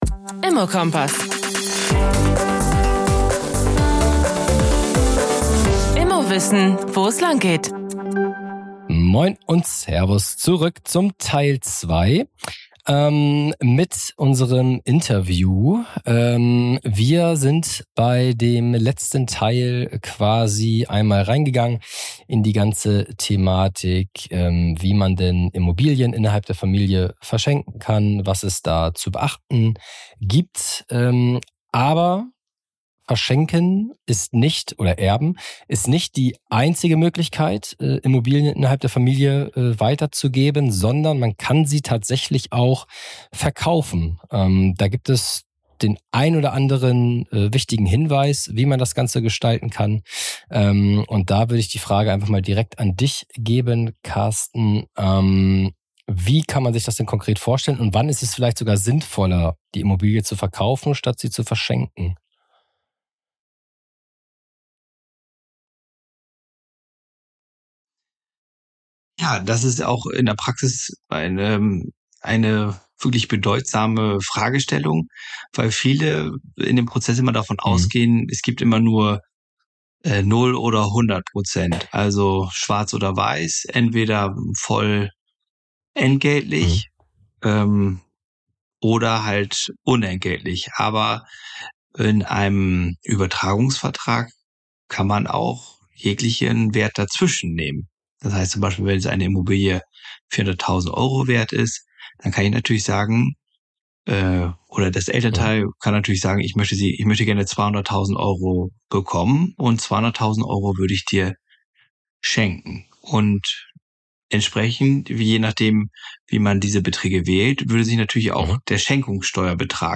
Wie kannst du innerhalb der Familie Vermögen weitergeben, ohne unnötige Steuern zu zahlen – und ohne Streit zu riskieren? In Teil 2 unseres Gesprächs mit Steuerberater